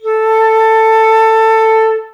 plt.title('Spectrum of Flute A4')